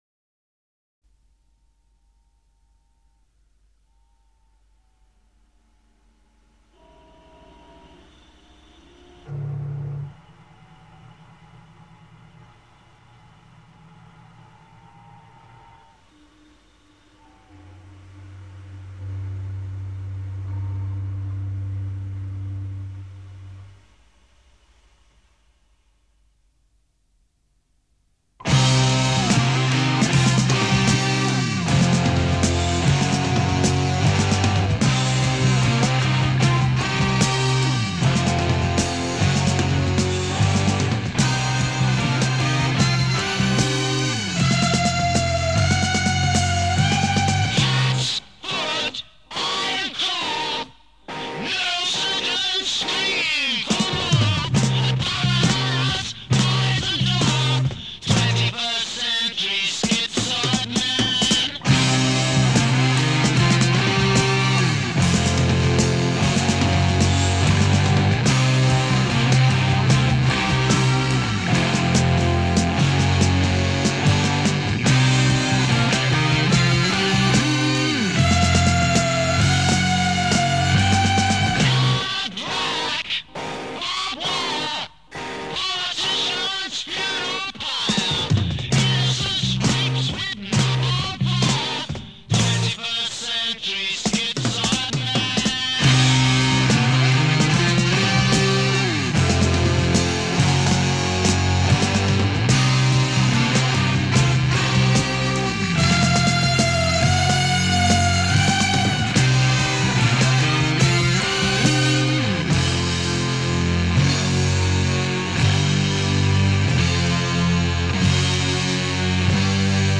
Introduction 27 seconds Electronic music
double-tracked, compressed, etc. a
6/8 jazz waltz; tight unison playing.
The first two section rise in octave creating direction.
guitar solo plays with feed back.
Transition 2 Drum break acting possibly as edit piece.
accelerates to pandemonium as ending.